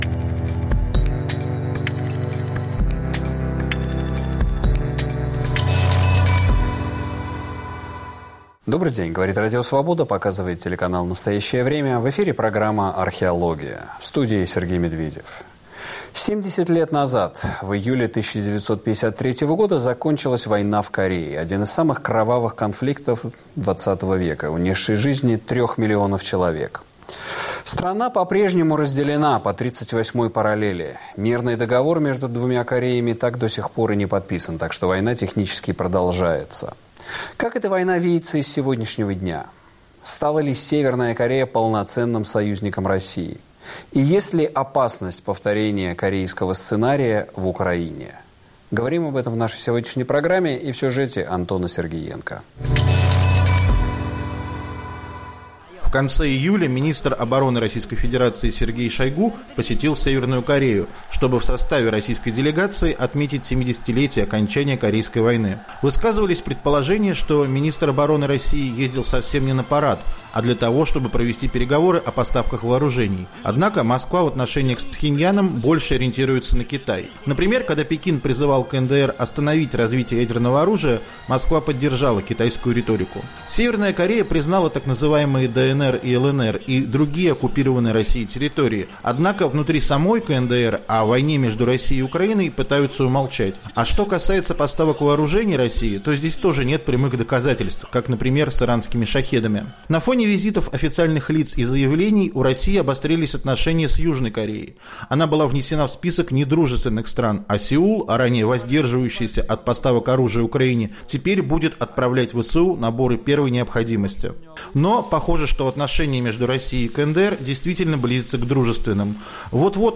Востоковед Андрей Ланьков
военный эксперт